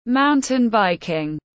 Môn xe đạp leo núi tiếng anh gọi là mountain biking, phiên âm tiếng anh đọc là /ˈmaʊn.tɪn ˌbaɪ.kɪŋ/ .
Mountain biking /ˈmaʊn.tɪn ˌbaɪ.kɪŋ/